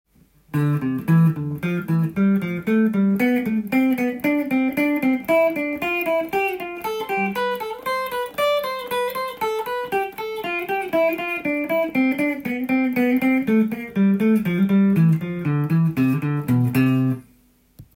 度数ごとのCメジャースケール練習】
２度進行
２度は、ドとレや　レからミ　などになります。